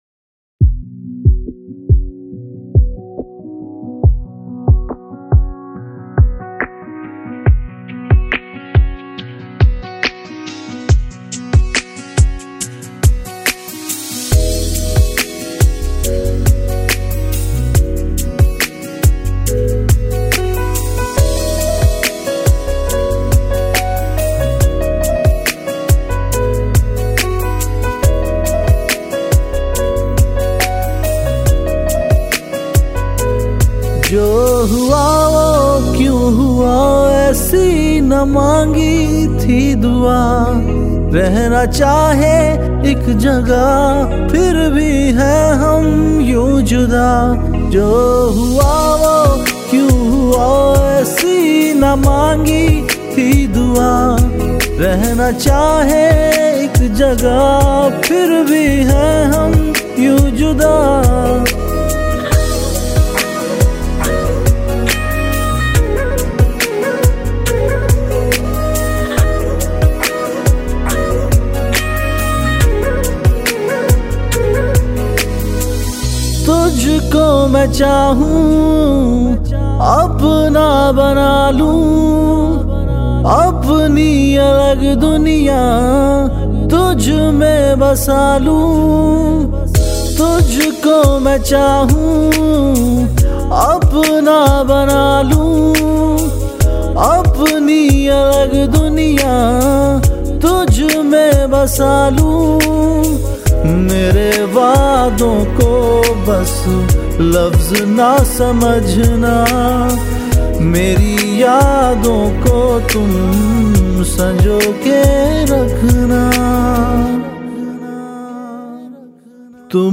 Single Pop Songs